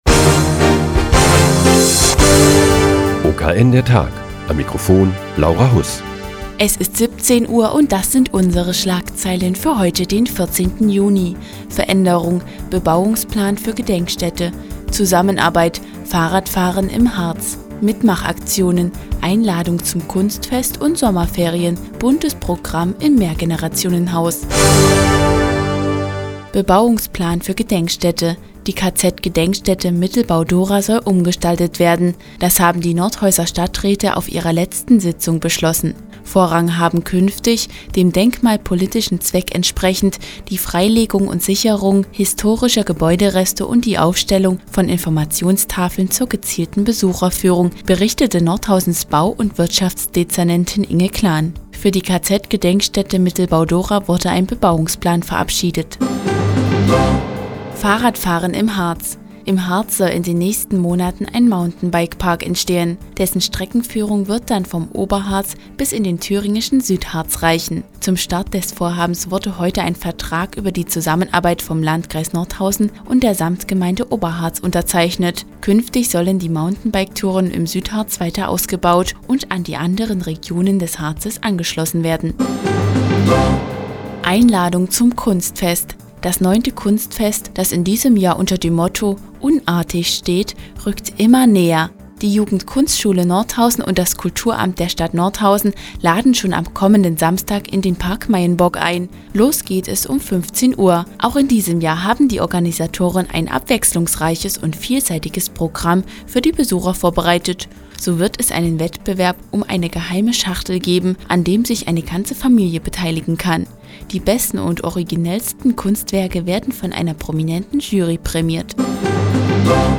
Die tägliche Nachrichtensendung des OKN ist nun auch in der nnz zu hören. Heute geht es um den Bebauungsplan für die Gedenkstätte Mittelbau-Dora und das diesjährige Kunstfest im Park Meyenburg.